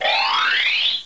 MG_pos_buzzer.ogg